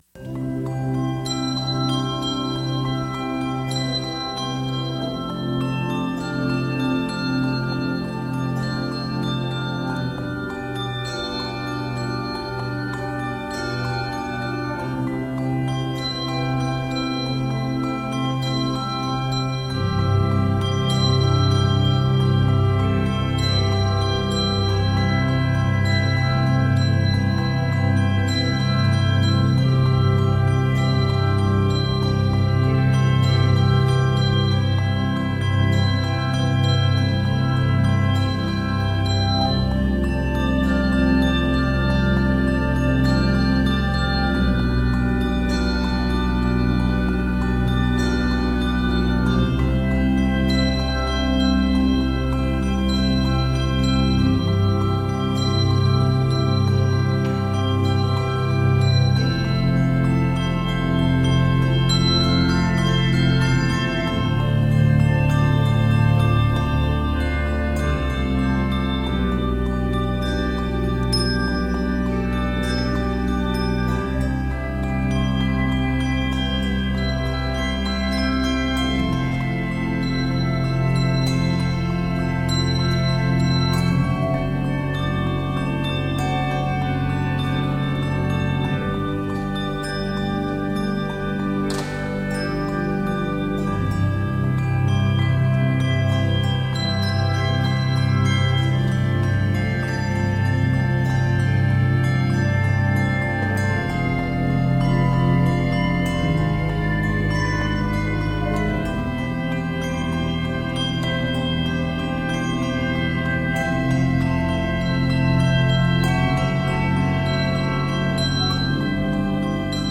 peaceful arrangement